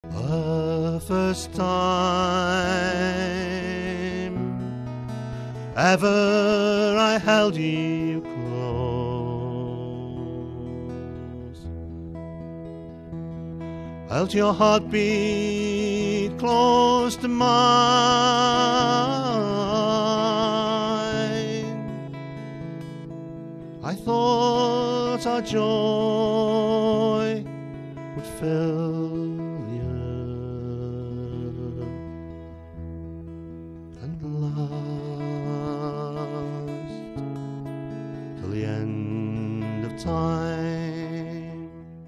Ashington Folk Club - Spotlight 21 July 2005
There was 12-string and 6-string guitar accompaniment and fine voice, very much enjoyed by everyone.